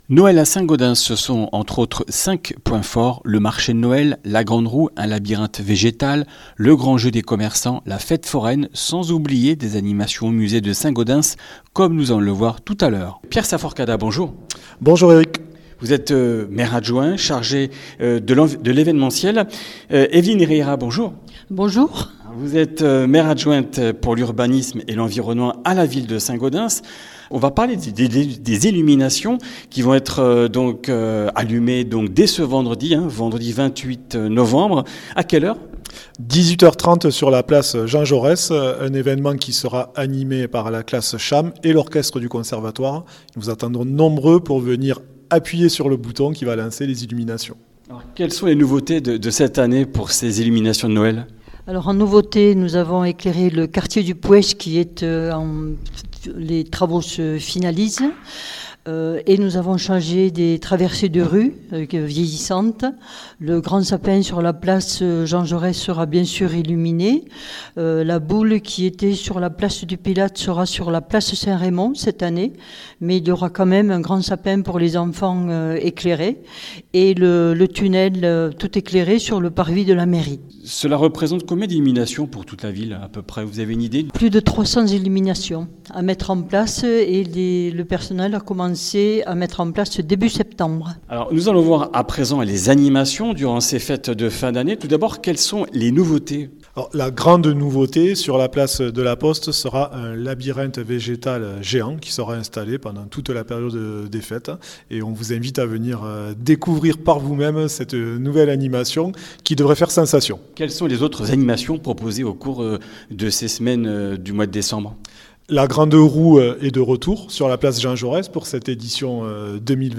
Comminges Interviews du 25 nov.